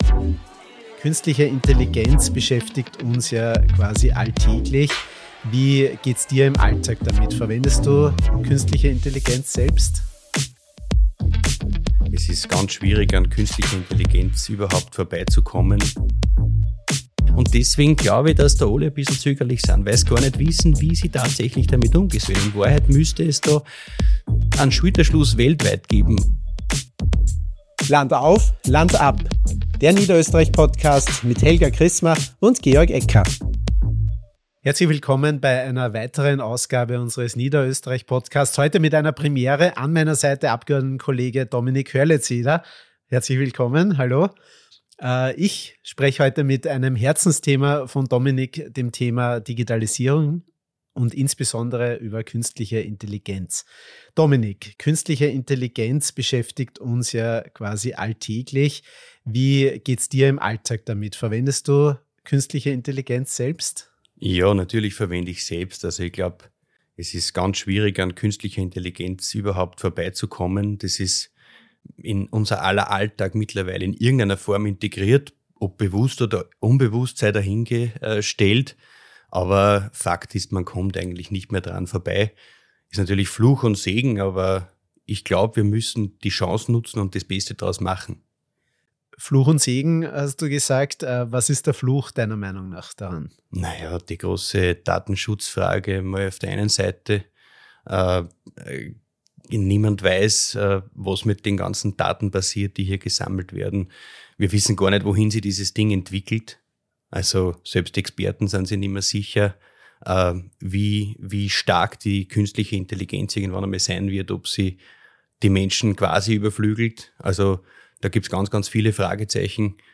Von Datenschutz über Deepfakes bis zur Kriegsführung: Ein tiefgreifendes Gespräch über die Technologie, die unsere Zukunft prägt.